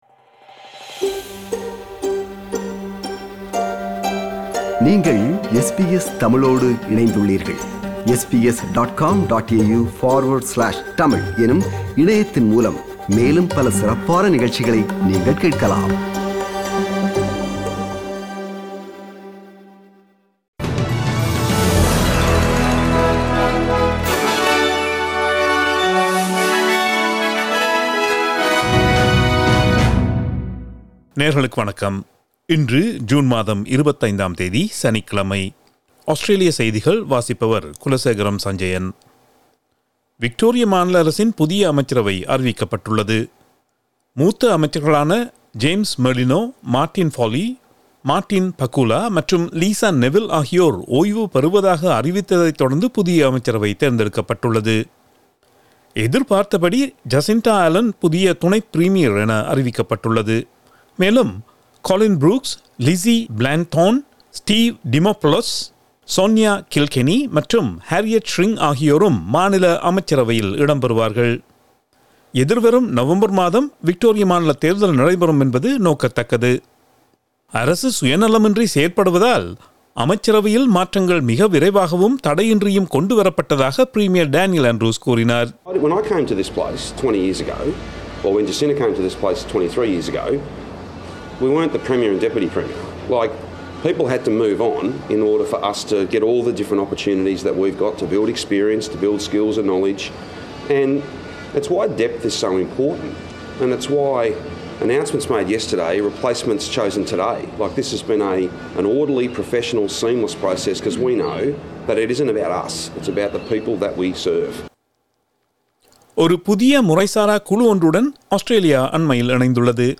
Australian news bulletin for Saturday 25 June 2022.